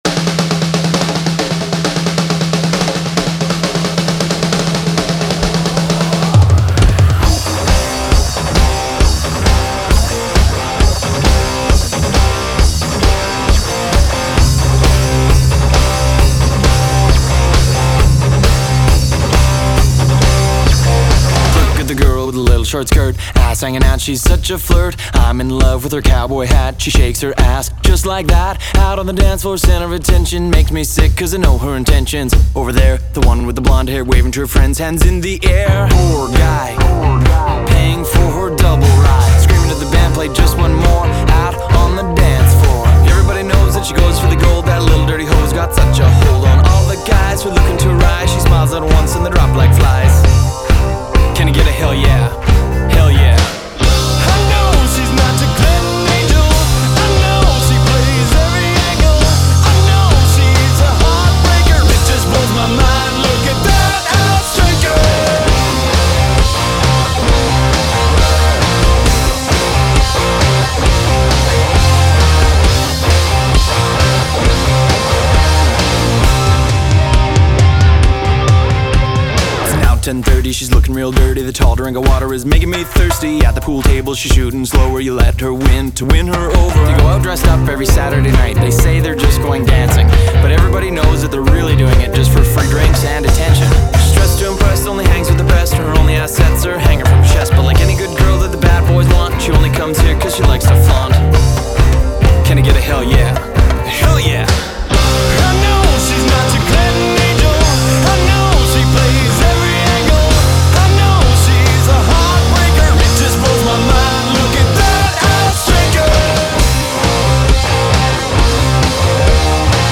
(multilayered recording)